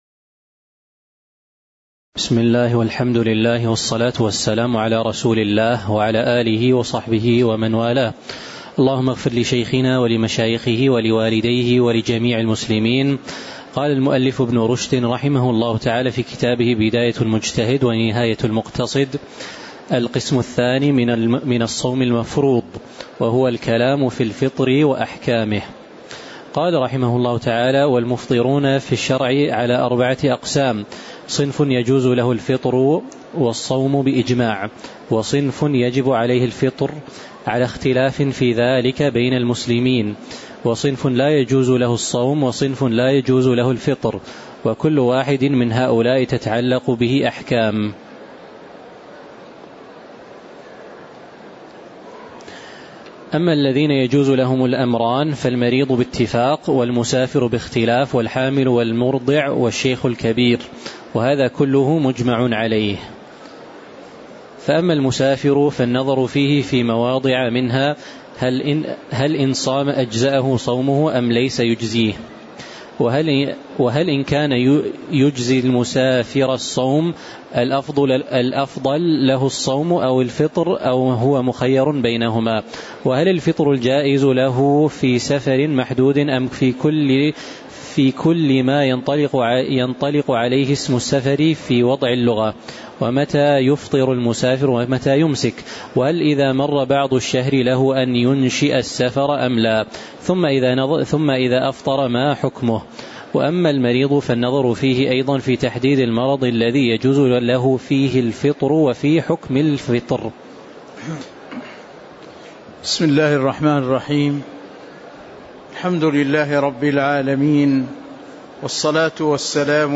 تاريخ النشر ١٥ جمادى الأولى ١٤٤٦ هـ المكان: المسجد النبوي الشيخ